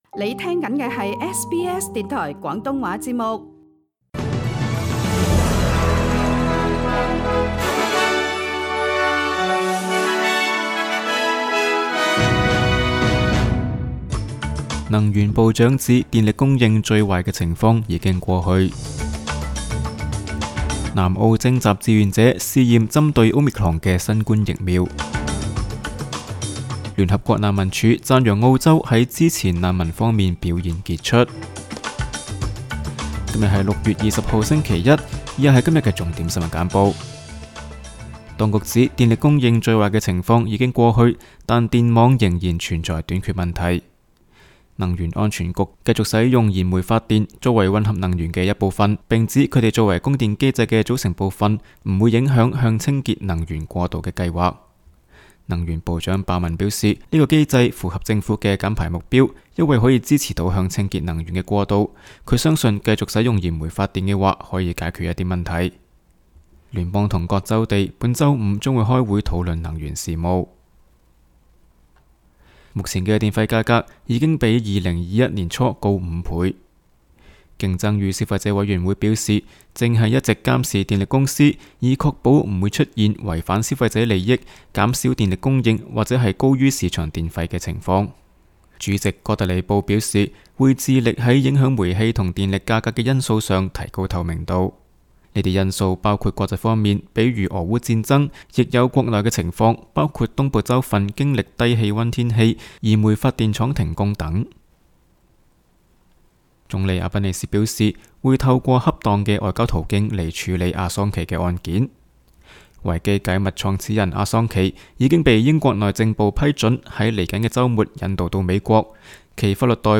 SBS 新闻简报（6月20日）
SBS 廣東話節目新聞簡報 Source: SBS Cantonese